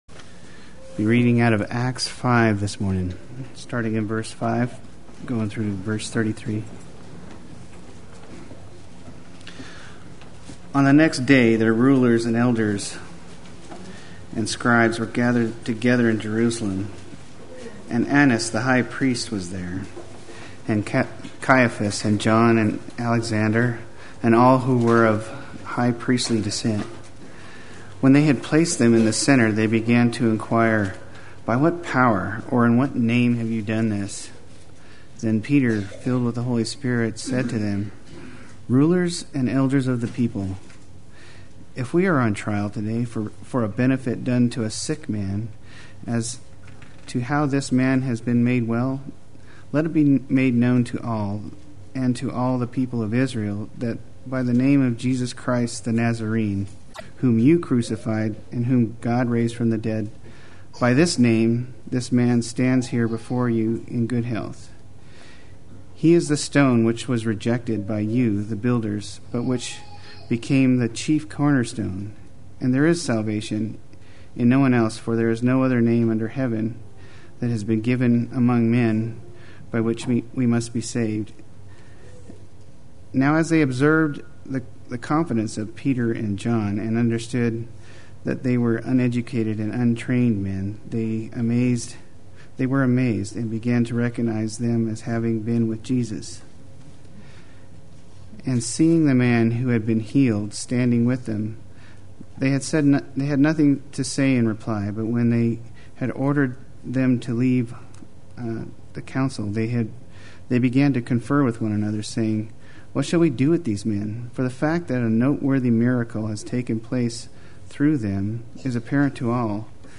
Play Sermon Get HCF Teaching Automatically.
Why the Resurrection is One of the Greatest Treasures Sunday Worship